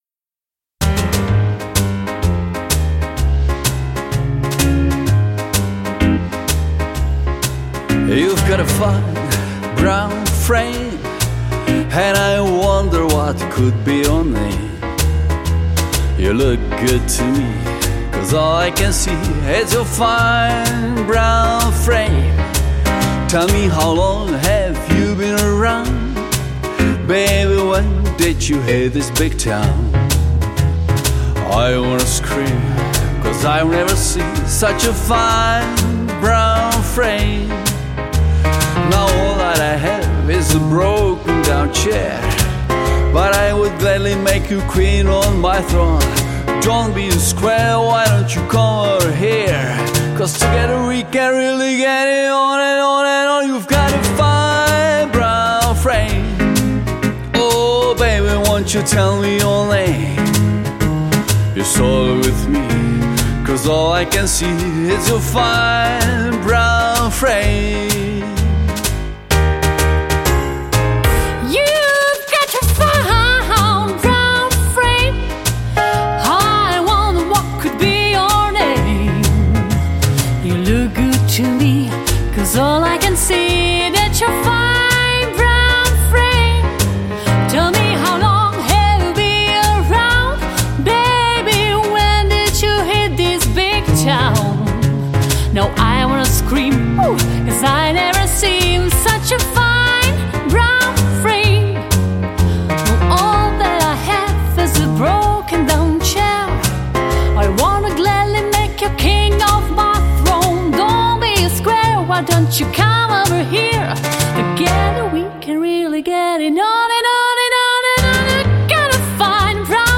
Jazz & Swing